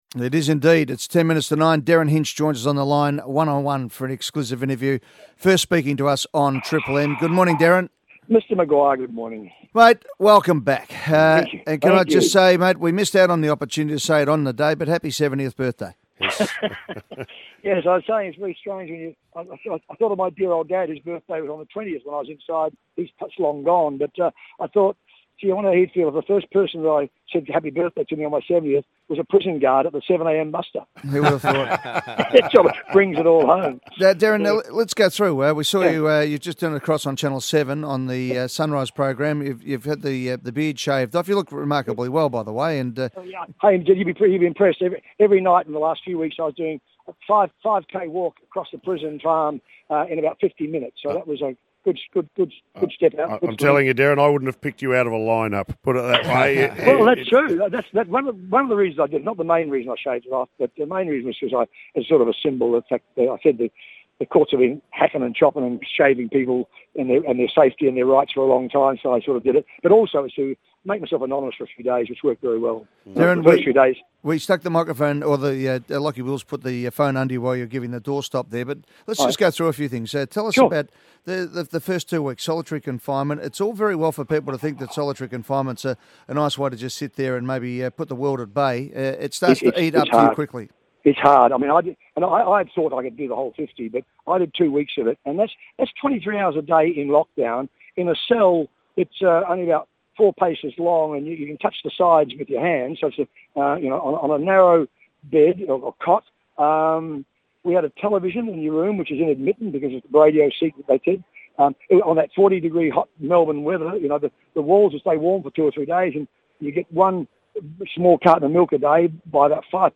Derryn Hinch speaking exclusively to Triple M's The Hot Breakfast after his release from jail.